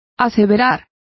Complete with pronunciation of the translation of affirm.